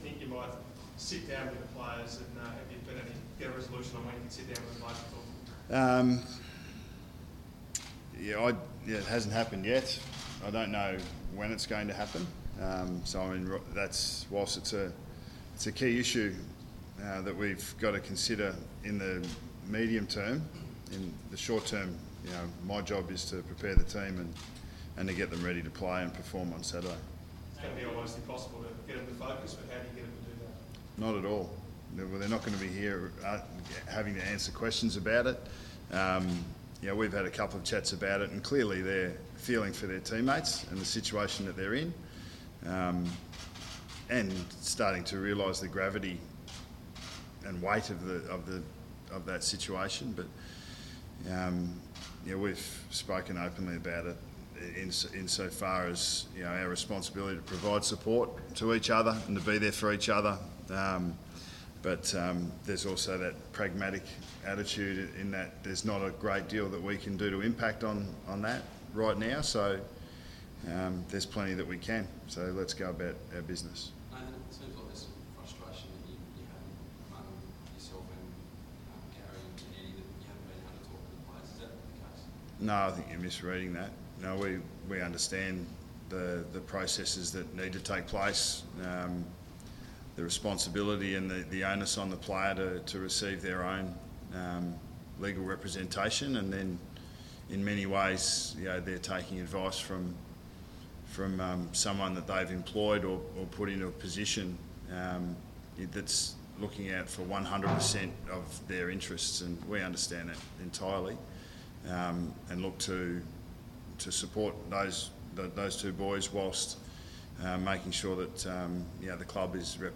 Press Conference: Nathan Buckley
Listen to coach Nathan Buckley take questions from the media following the news that Lachlan Keeffe and Josh Thomas are under investigation from ASADA on Tuesday 31 March.